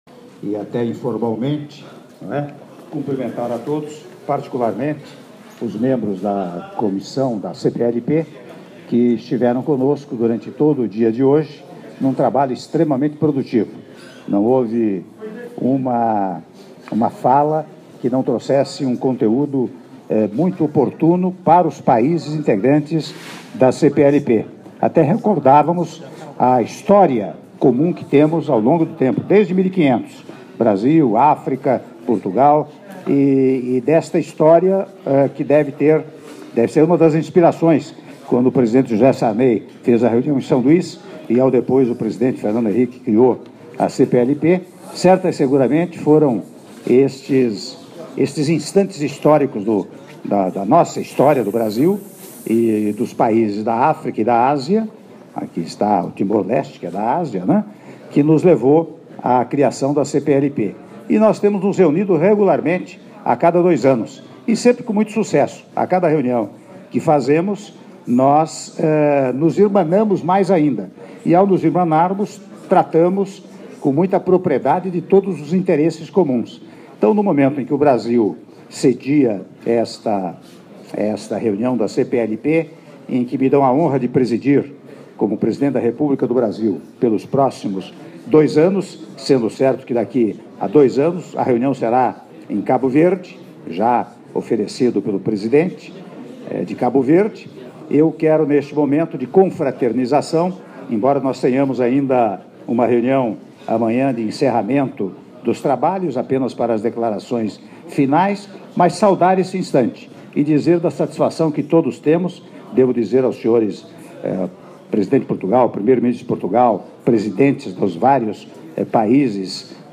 Áudio do brinde do presidente da República, Michel Temer, durante jantar em homenagem aos Chefes de Delegação da XI Conferência de Chefes de Estado e de Governo da CPLP oferecido pelo Presidente da República - (03min01s) - Brasília/DF